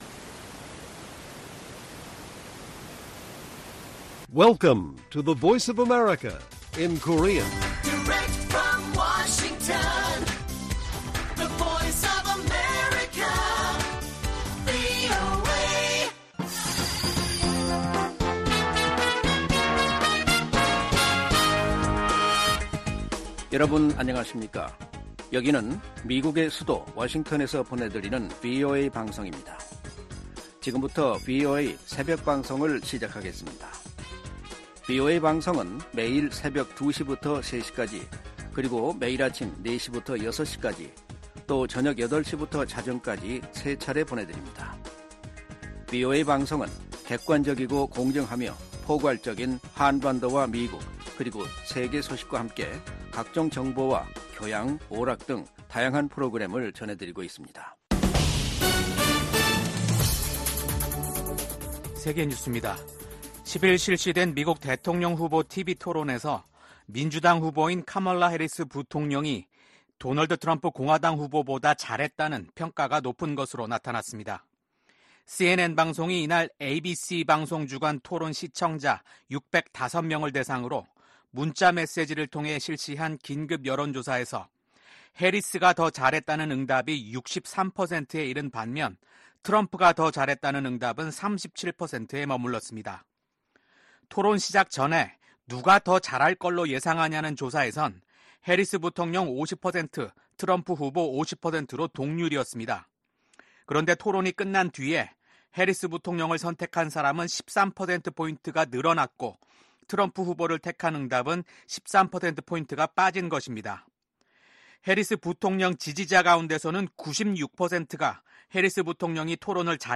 VOA 한국어 '출발 뉴스 쇼', 2024년 9월 12일 방송입니다. 오는 11월 대선에서 맞붙는 카멀라 해리스 부통령과 도널드 트럼프 전 대통령이 TV 토론회에 참석해 치열한 공방을 벌였습니다. 미국, 한국, 일본 간 협력 강화를 독려하는 결의안이 미 하원 본회의를 통과했습니다. 서울에선 68개 국가와 국제기구 고위 인사들이 참석한 가운데 다자 안보회의체인 서울안보대화가 열렸습니다.